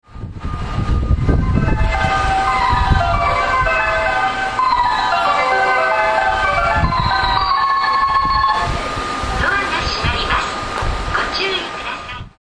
メロディーは一般的です。